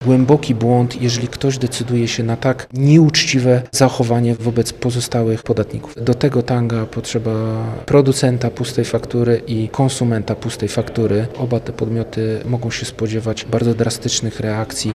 Wiadomości
Nowe przepisy, nad którymi pracują urzędnicy, mają umożliwić identyfikacje oszustów podatkowych – nie tylko w bieżącym rozliczeniu, ale także we wcześniejszych – mówi wiceminister Paweł Gruza.